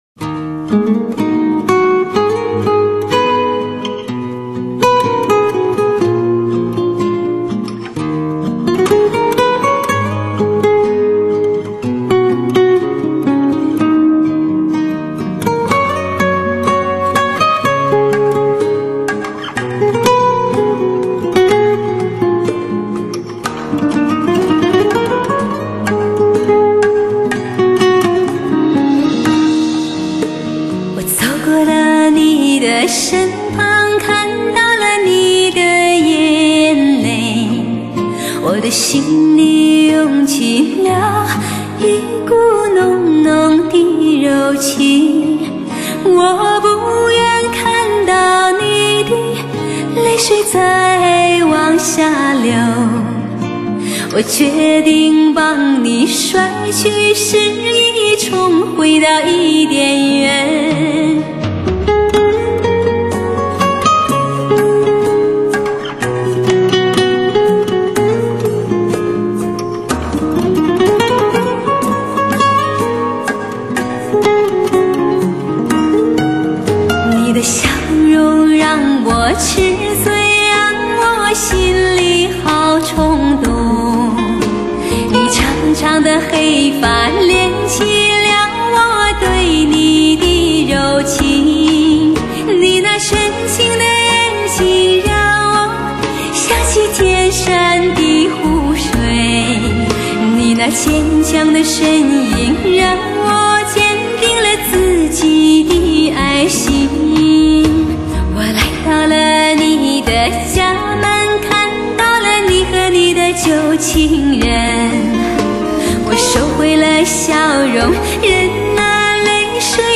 美妙的民族音乐盛宴